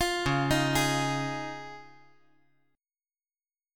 Dbadd9 Chord
Listen to Dbadd9 strummed